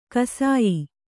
♪ kasāyi